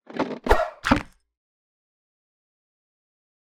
longbow-003-60ft.ogg